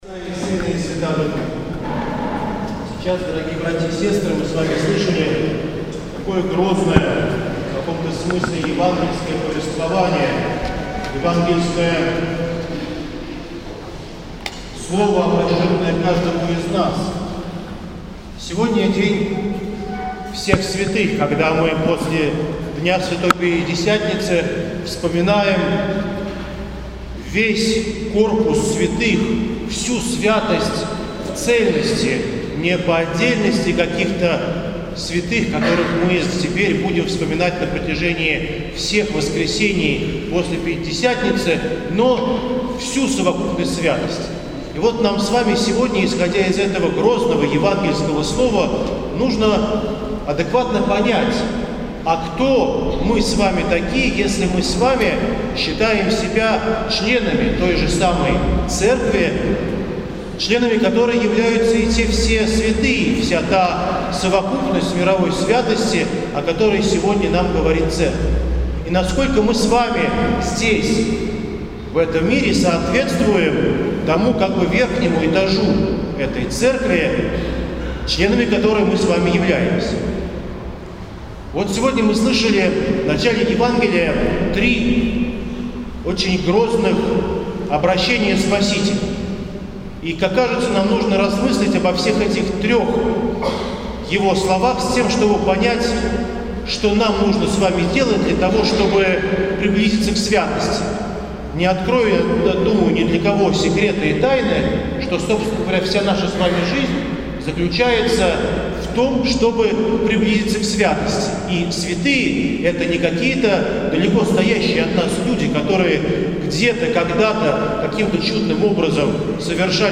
Литургия